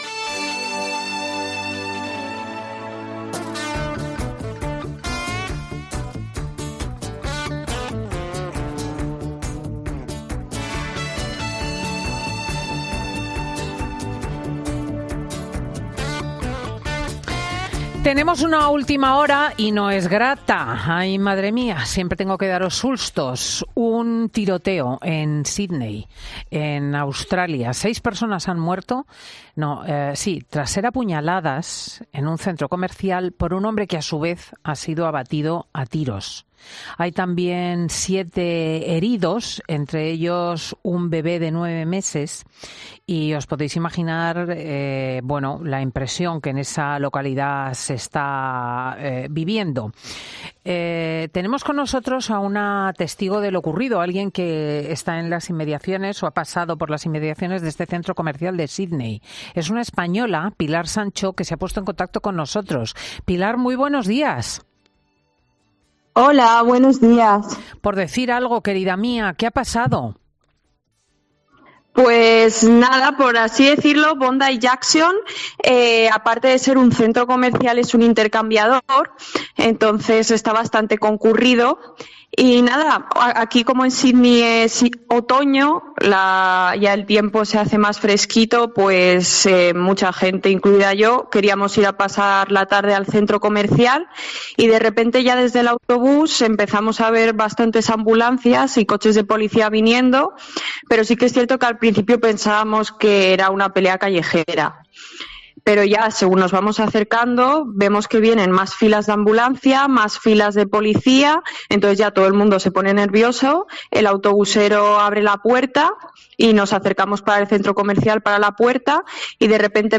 "Hemos salido corriendo, una angustia...Porque no sabíamos de quién huíamos ni qué pasaba ni dónde ir" expresaba con angustia.